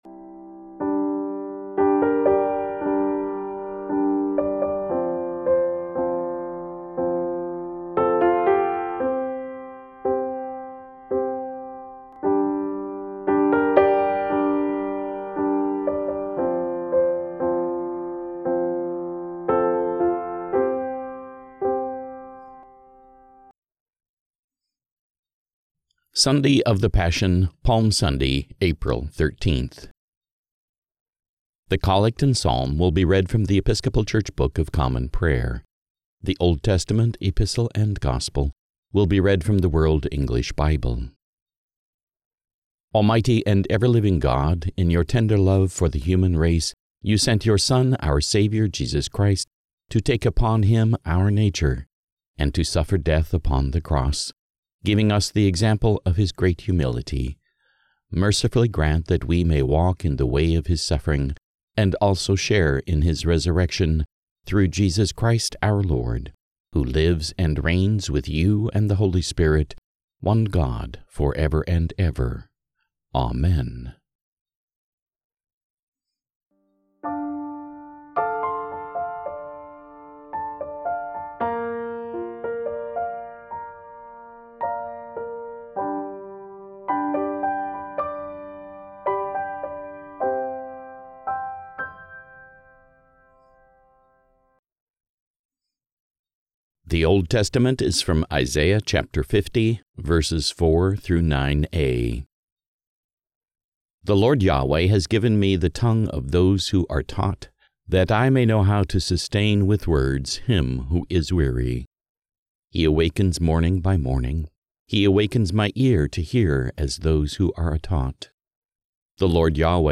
The Collect and Psalm will be read from The Episcopal Church Book of Common Prayer
The Old Testament, Epistle and Gospel will be read from The World English Bible.